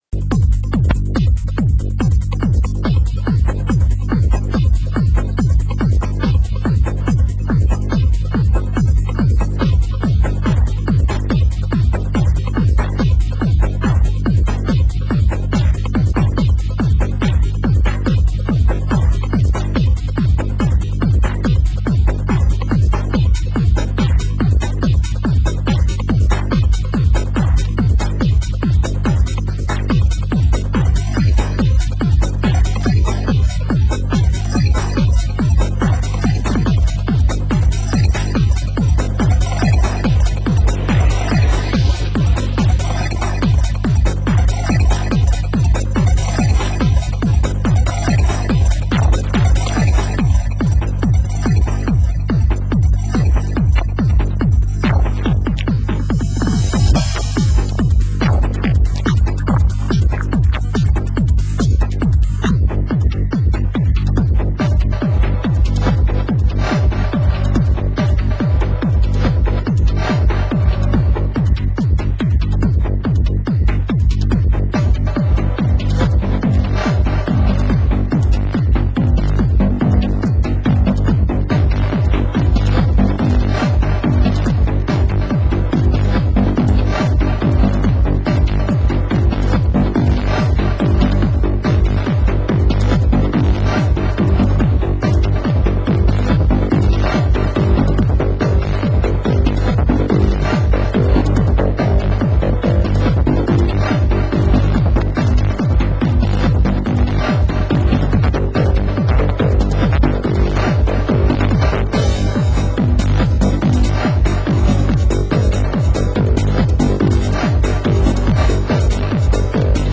Genre: Hard House